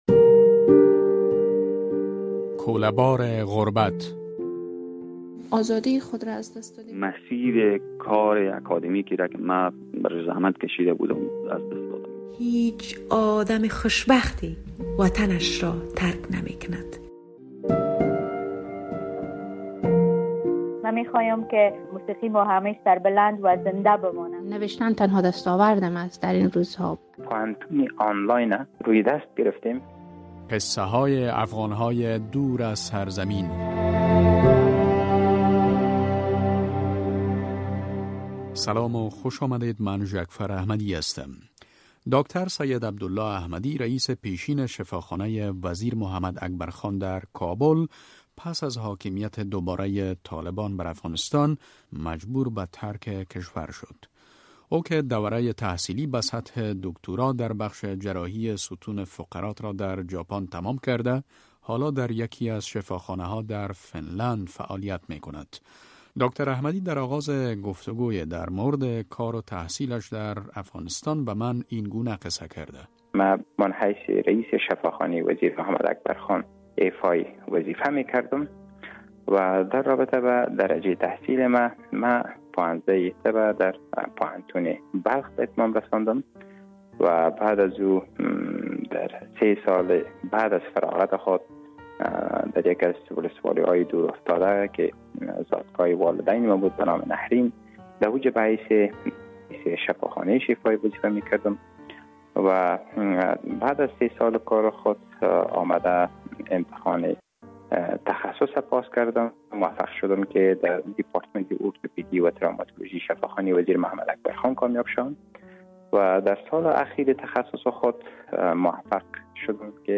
رادیو آزادی سلسله‌ای از گفت‌و‌گو های جالب با آن عده از شهروندان افغانستان را آغاز کرده است که پس از حاکمیت دوبارۀ طالبان بر افغانستان، مجبور به ترک کشور شده اند.